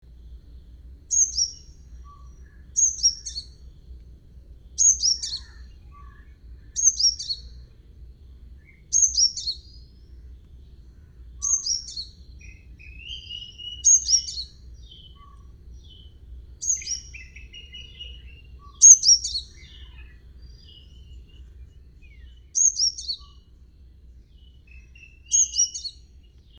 (PURPLE-RUMPED SUNBIRD)
purple-rumped-sunbird-pilerne-goa-15-oct-07-editedx-c.mp3